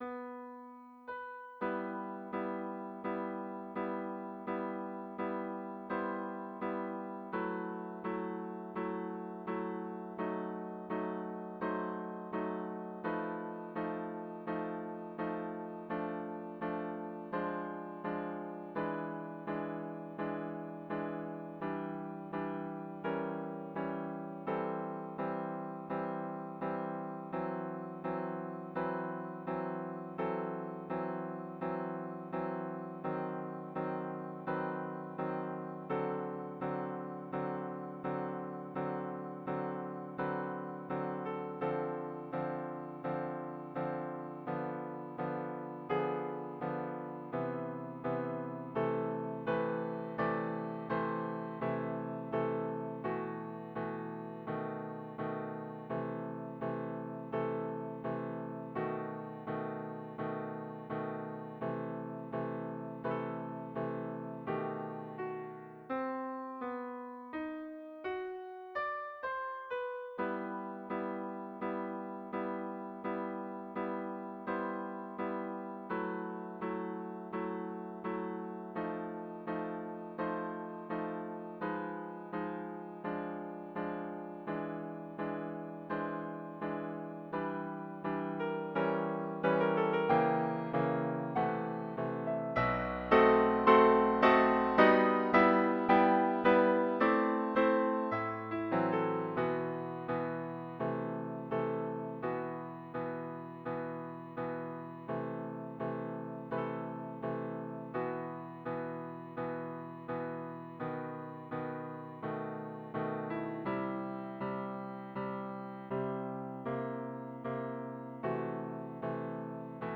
Genre 🎹 Piano, 🎹 Classical Piano, 🎹 Nhạc Piano cổ điển